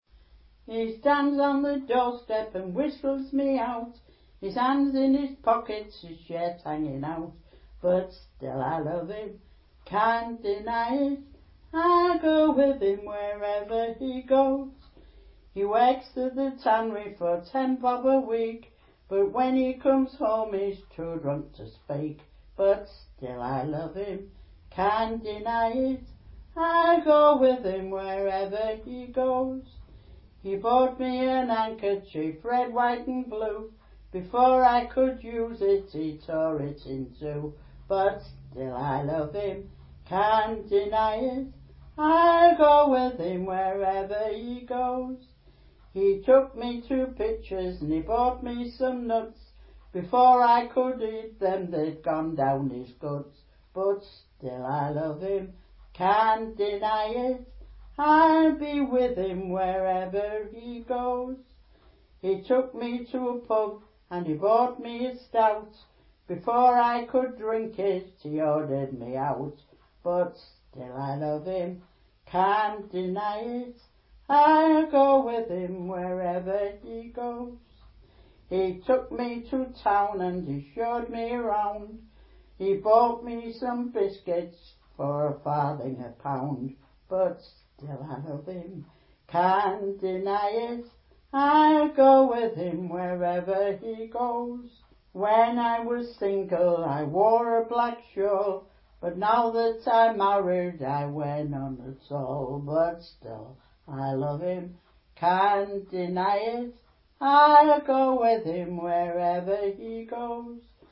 Hull